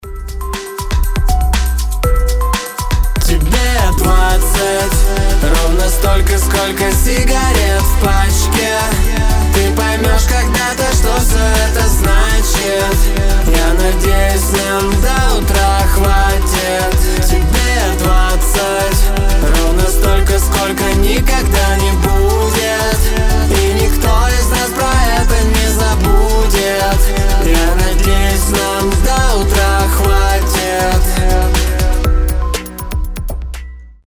• Качество: 320, Stereo
поп
мужской вокал
мелодичные